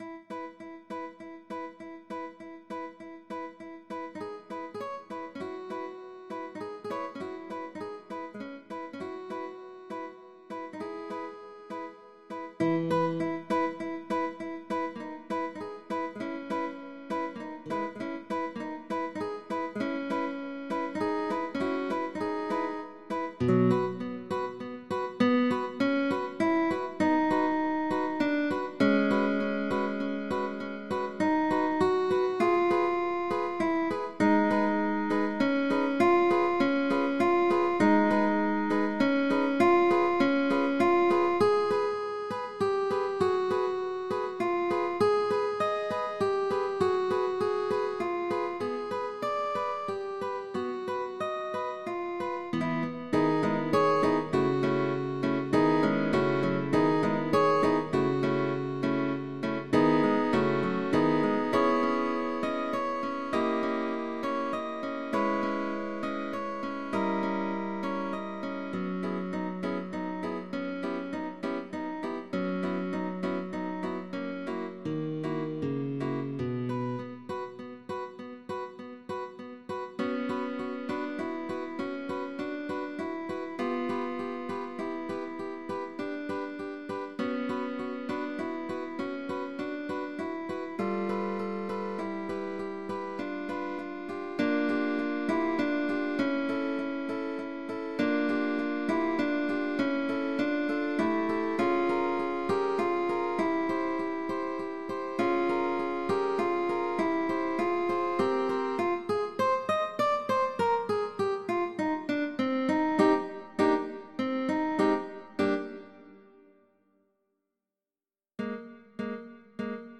The original score by piano is arranged by guitar duo.
GUITAR DUO